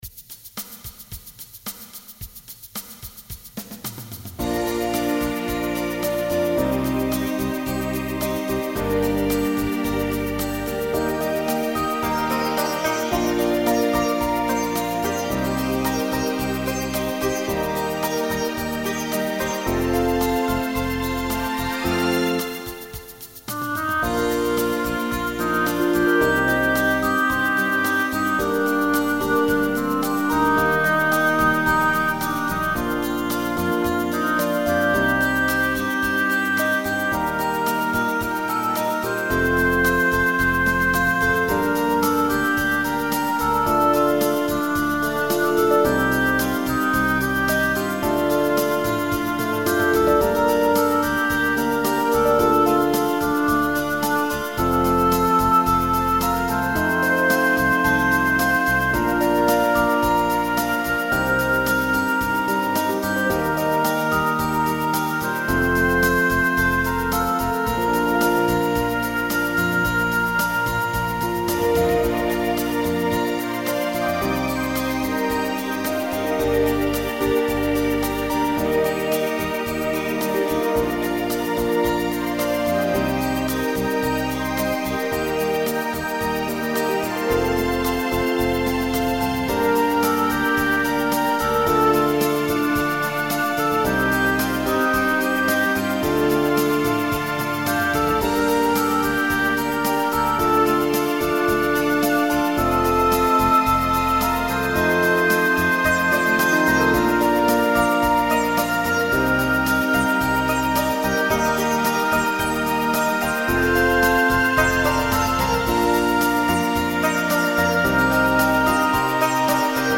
Recording from MIDI